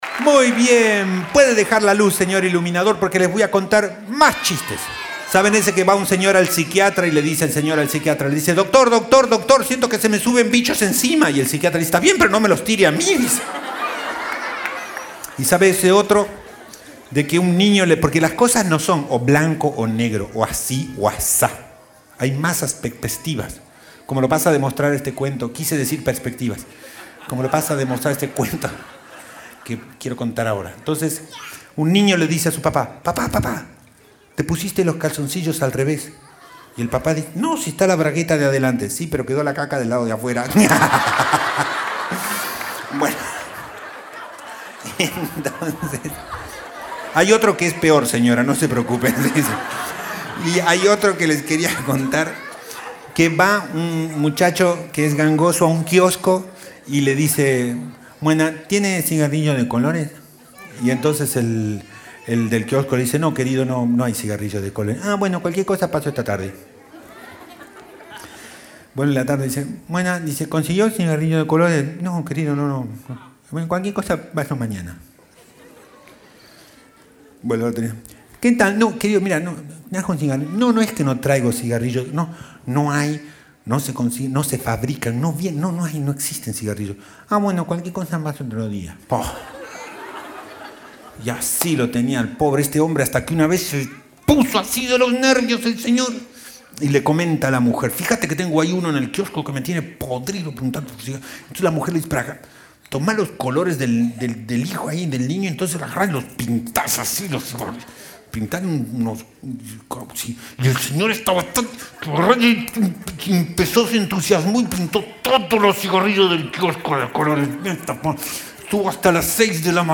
(Tonalidad F Mayor)
Dm | Dm| C | C :||